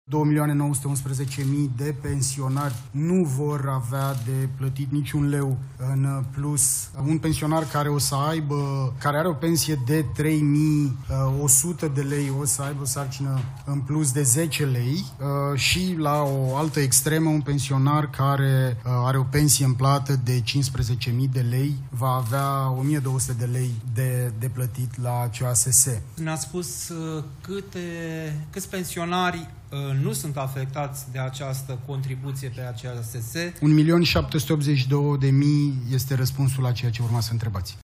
Prezentare maraton la Palatul Victoria: vicepremierul Tanczos Barna alături de miniștrii Finanțelor, Muncii, Sănătății și Educației au prezentat măsurile din pachetul fiscal pentru care Guvernul își va asuma răspunderea săptămâna viitoare.
Explicații au venit de la ministrul Muncii la Palatul Victoria.